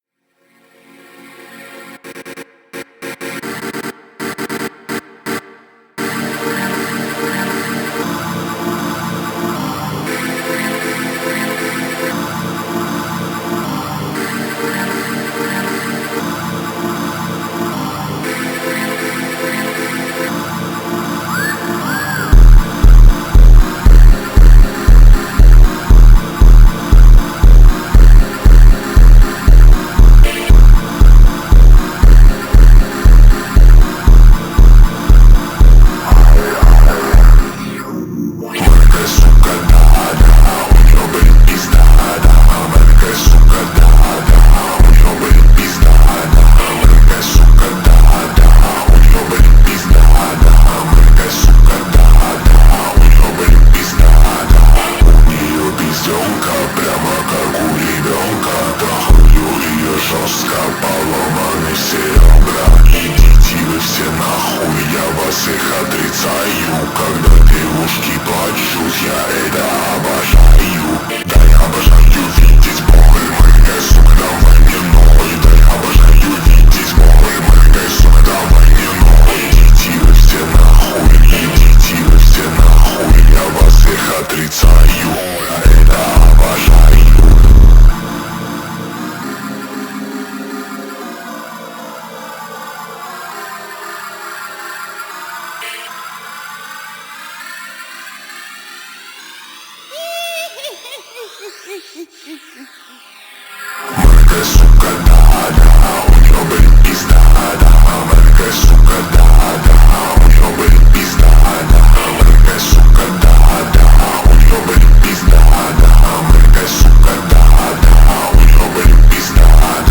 (slowed + reverb + bass boosted)